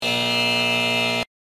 descargar-efecto-de-sonido-falla-error-en-hd-sin-copyright.mp3